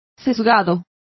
Complete with pronunciation of the translation of slanting.